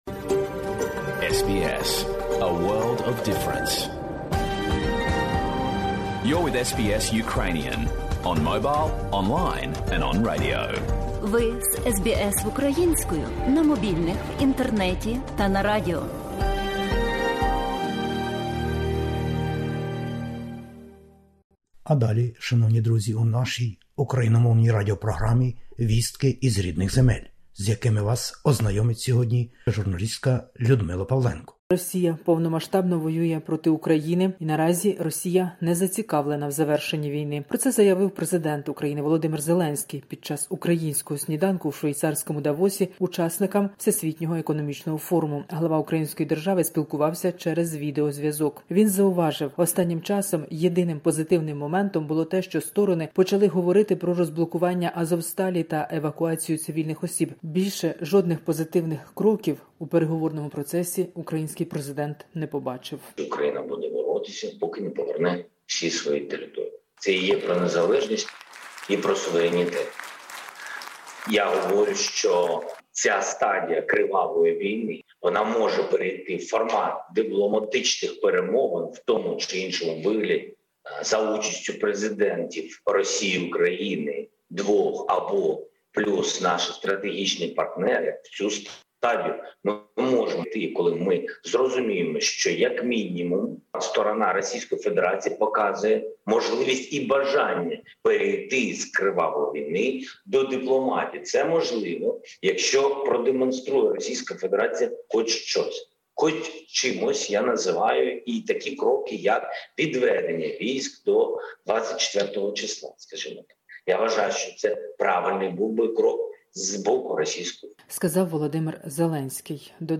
Добірка новин із героїчної України спеціально для радіослухачів SBS Ukrainian.
ukr_news_test.mp3